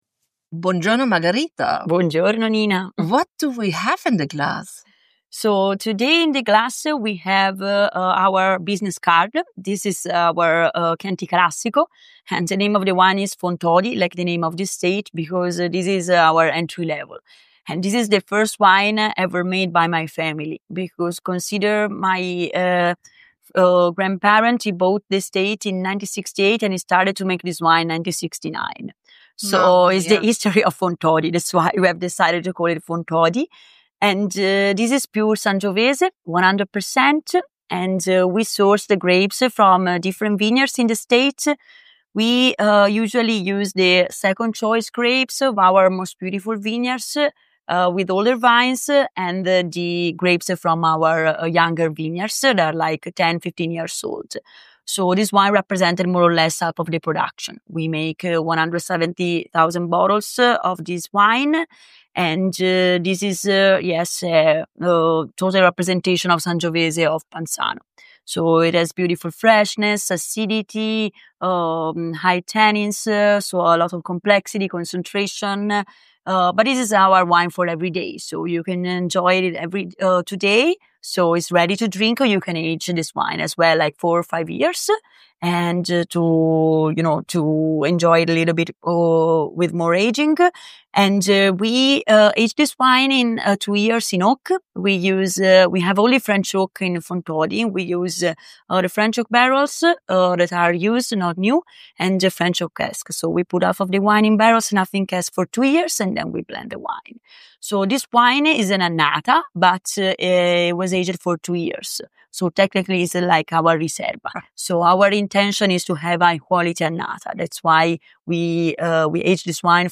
Ein Gespräch über Herkunft, Leidenschaft und die neue Generation im Chianti Classico, in Englisch.